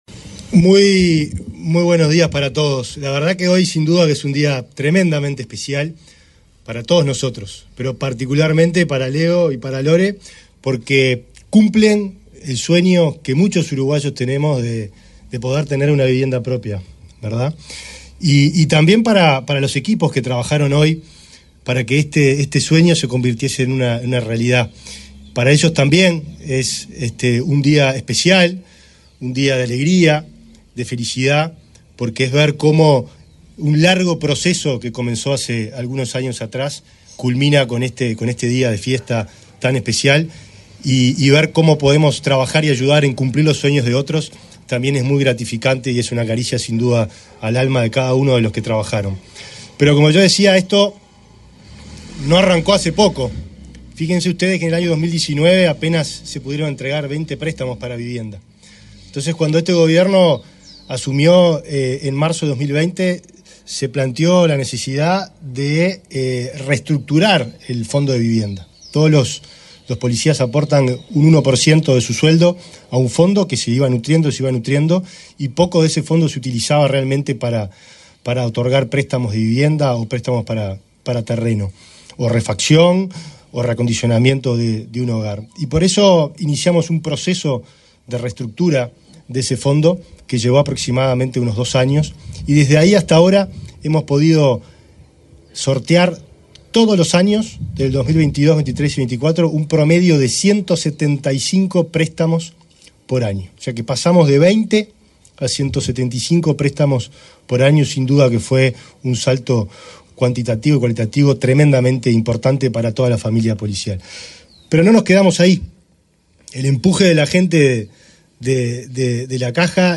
Palabras de autoridades en acto en Salinas
Palabras de autoridades en acto en Salinas 12/11/2024 Compartir Facebook X Copiar enlace WhatsApp LinkedIn El titular del Ministerio del Interior, Nicolás Martinelli, y el ministro interino de Vivienda, Tabaré Hackenbruch, participaron en el acto de entrega de una vivienda a un agente policial, en el marco del plan Avanzar, en el balneario Salinas, departamento de Canelones.